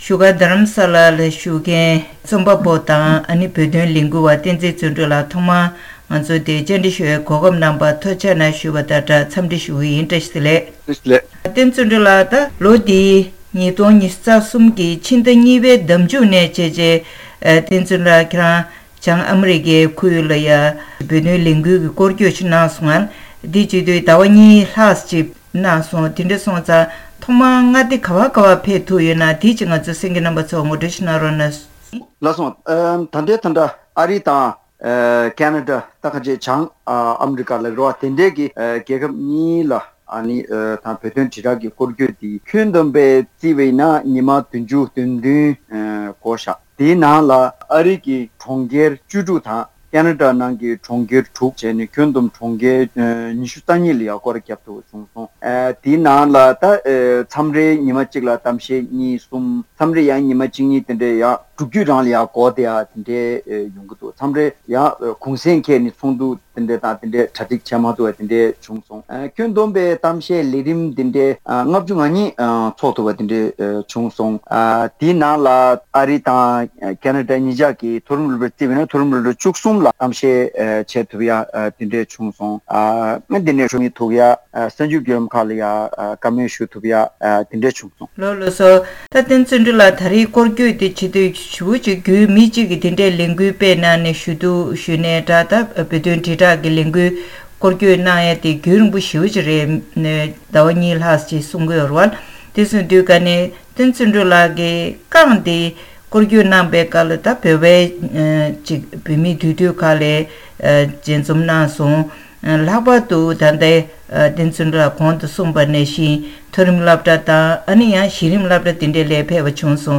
བཀའ་འདྲི་ཞུས་པ་ཞིག